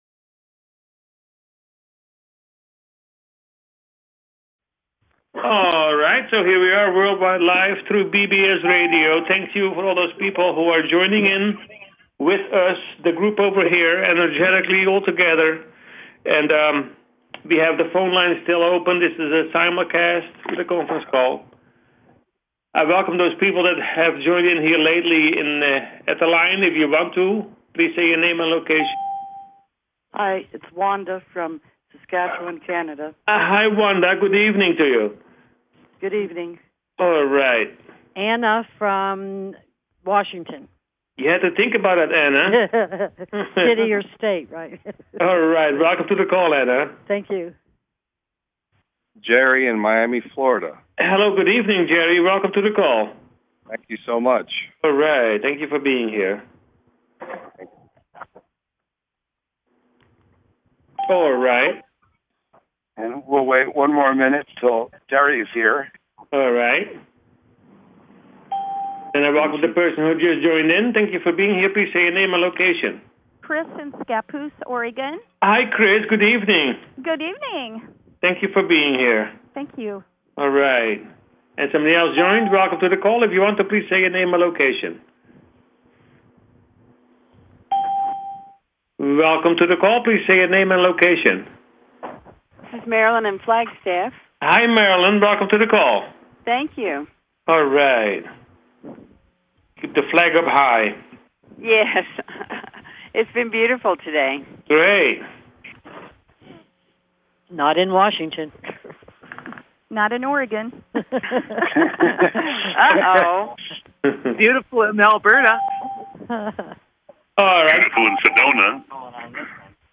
Talk Show Episode, Audio Podcast, You_Got_Questions_We_Got_Answers and Courtesy of BBS Radio on , show guests , about , categorized as